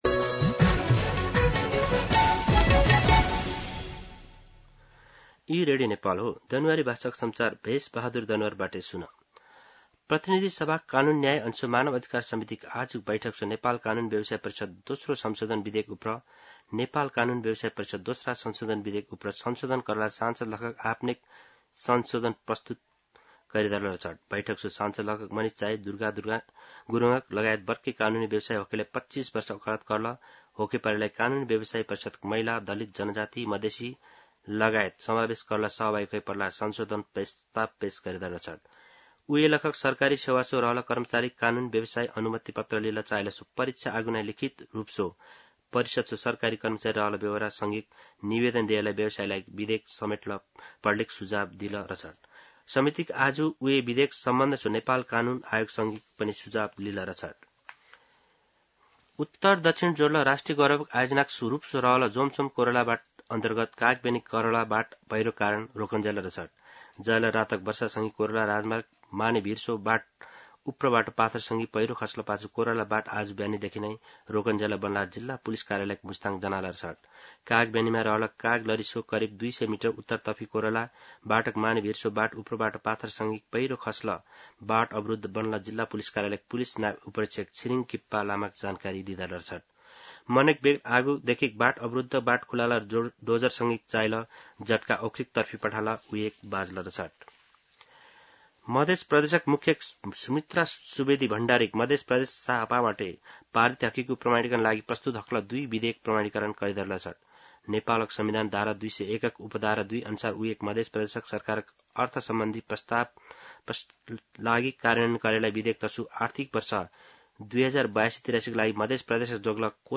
An online outlet of Nepal's national radio broadcaster
दनुवार भाषामा समाचार : ३२ असार , २०८२
Danuwar-News-3-32.mp3